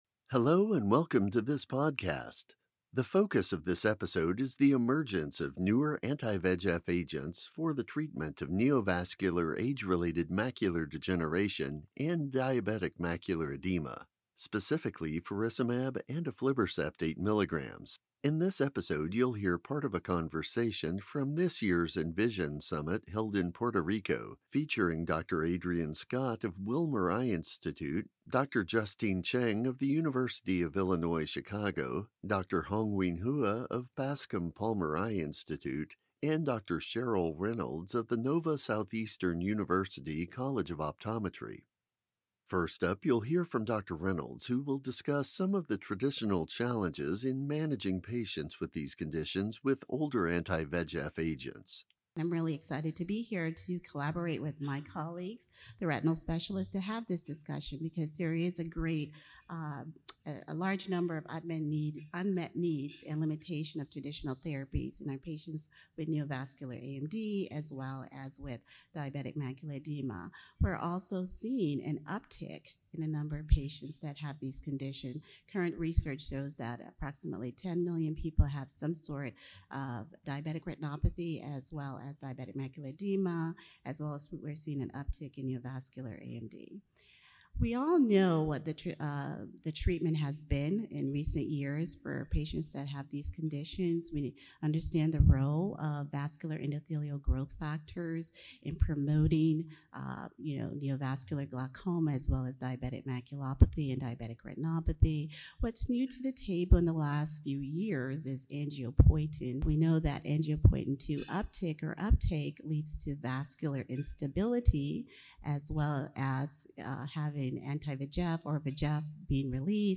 In this podcast, expert faculty discuss the roles of ophthalmologists and optometrists in managing nAMD and DME in an evolving treatment landscape, from screening and diagnosis to referral and ongoing patient care.